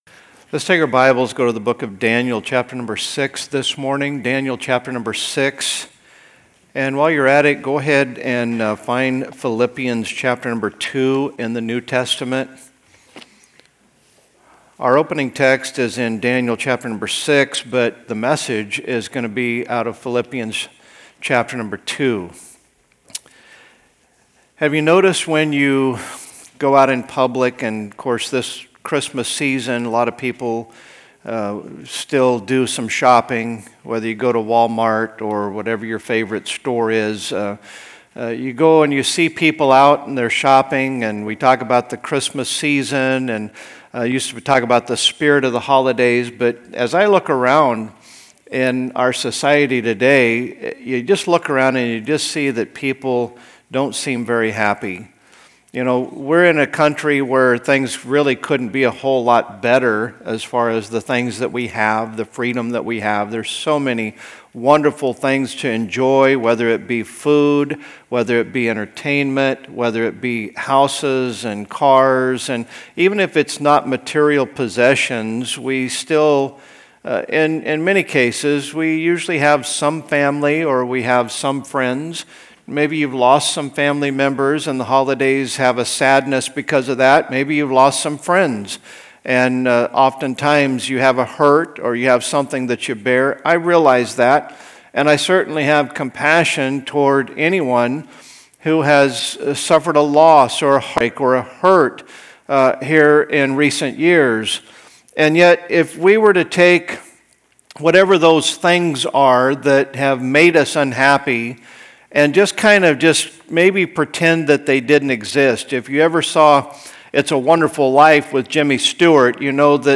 A sermon on how Scripture shapes us spiritually.